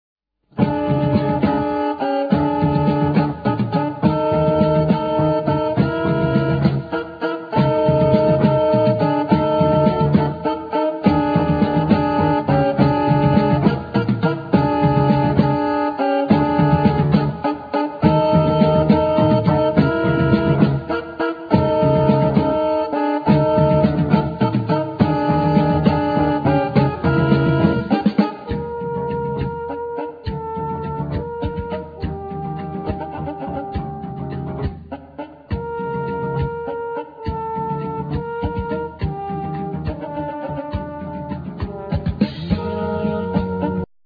Vocal
Saxophone
Guitar
Bass
Trumpet
Drums
Flute
Violin
Piano
Keyboards
Soprano